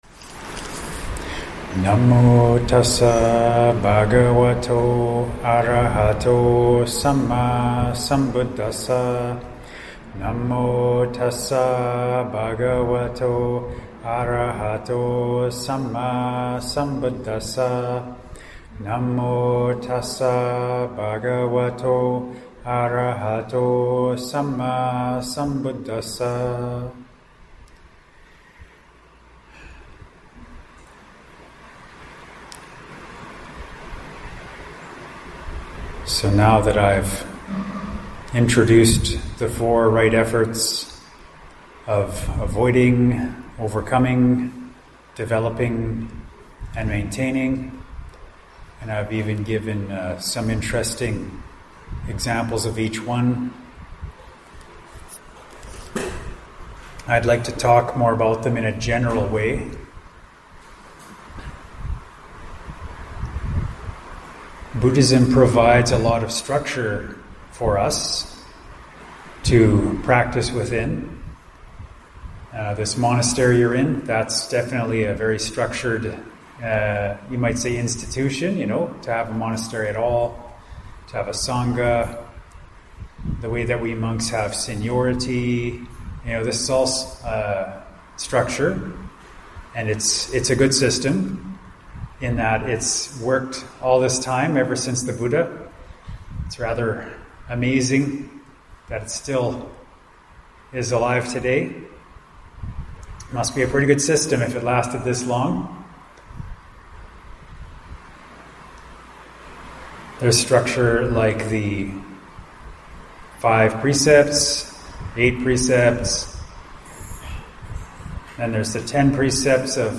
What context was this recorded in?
Recorded at Brahmavihara Buddhist Monastery, Malaysia, to an audience of about 17.